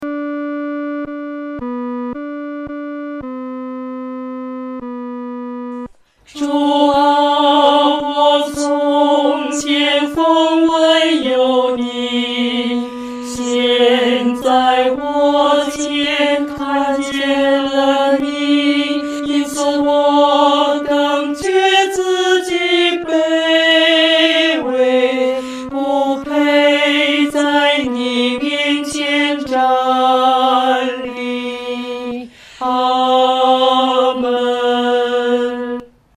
女低
指挥在带领诗班时，表情和速度应采用温柔而缓慢地。